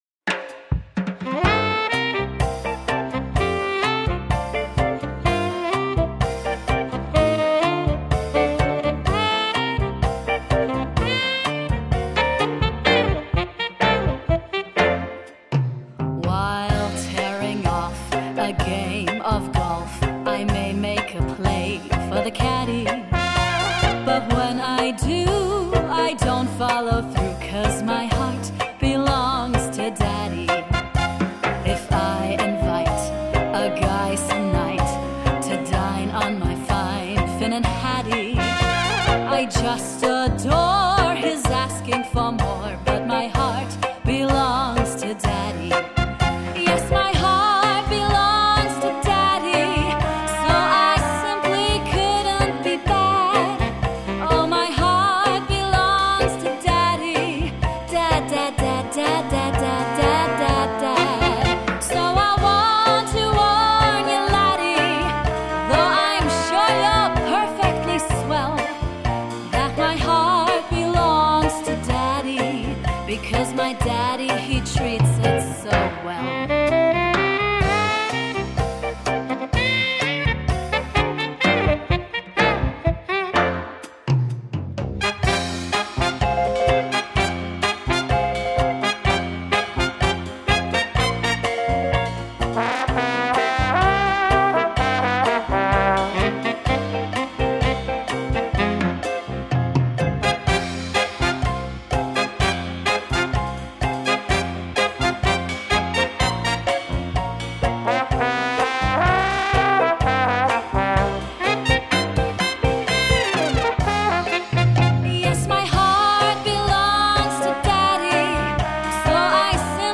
• Jazz
• Pianist / Orgelspieler
• Sänger/in